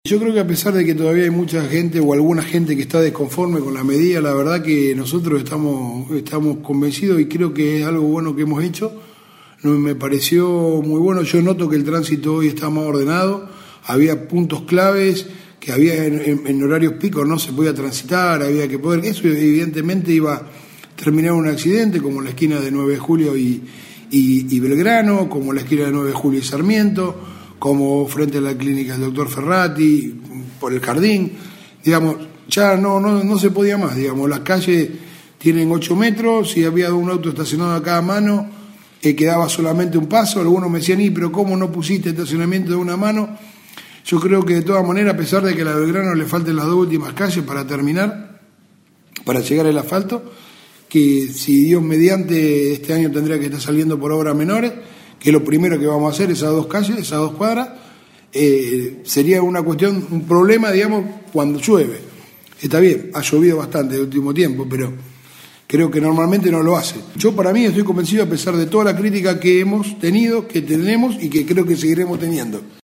En diálogo con Radio EME, hizo referencia a la futura sucursal de Banco Nación, la habilitación de la sala de faena, la nueva unidad móvil y el equipamiento del para el CIC, el comienzo de la obra de la ciclovía, el estado de las calles y su reparación, la necesidad de mejoras en el basural, y los trabajos que se hacen para tratar de evitar volver a sufrir inconvenientes en caso de lluvias.